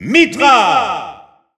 Announcer pronouncing Mythra's name in French.
Category:Mythra (SSBU) Category:Announcer calls (SSBU) You cannot overwrite this file.
Mythra_French_Announcer_SSBU.wav